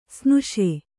♪ snuṣe